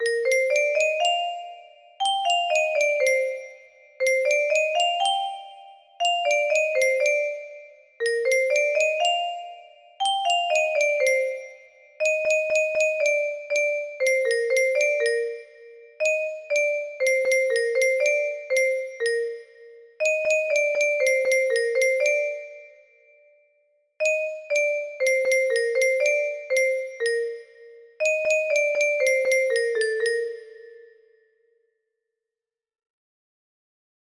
Simple Test Melody music box melody